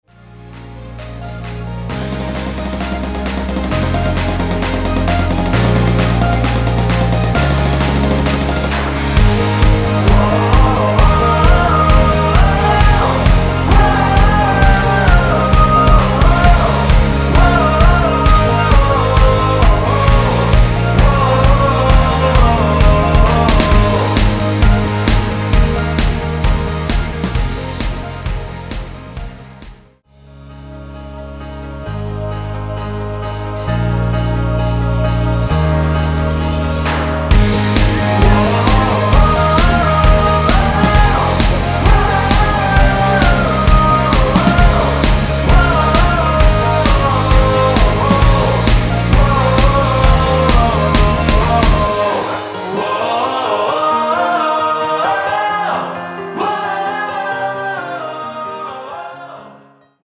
원키에서(-1)내린 코러스 포함된 MR입니다.(미리듣기 확인)
Ab
앞부분30초, 뒷부분30초씩 편집해서 올려 드리고 있습니다.